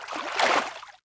fishing_fail.wav